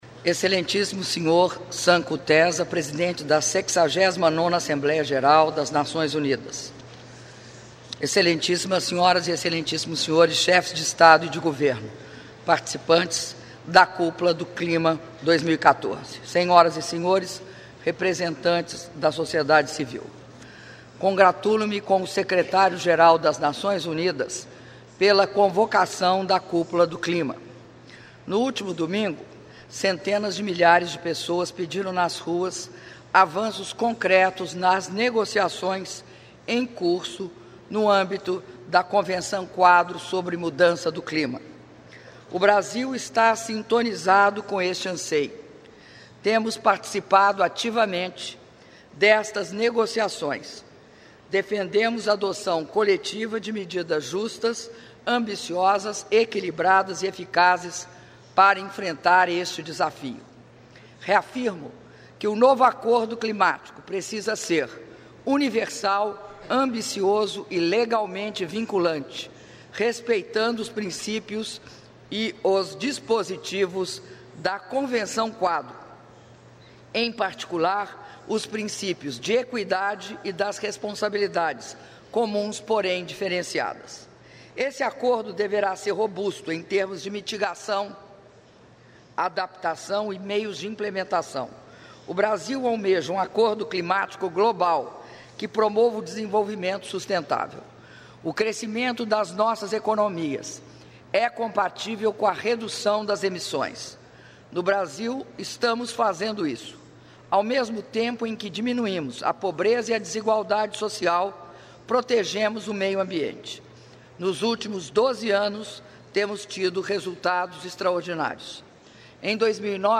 Áudio do discurso da Presidenta da República, Dilma Rousseff, na Cúpula do Clima 2014, na sede da ONU - Nova Iorque/EUA (07min34s)